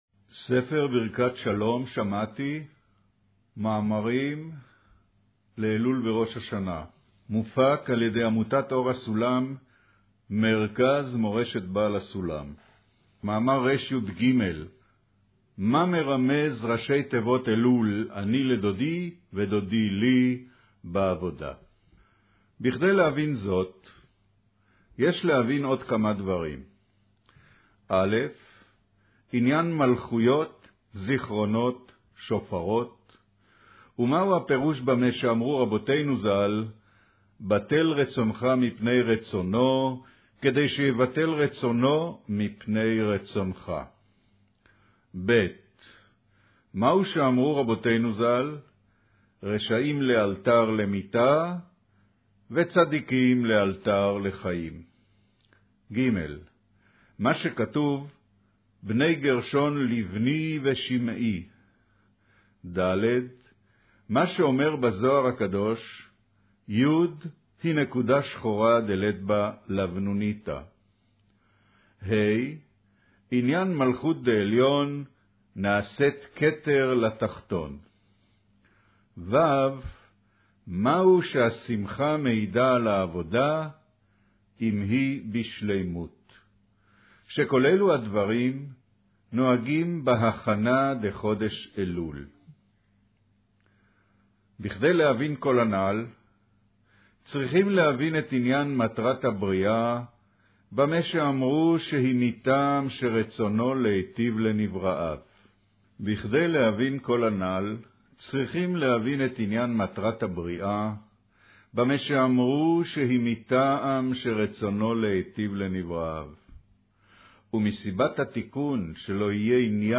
קריינות מאמר אני לדודי ודודי לי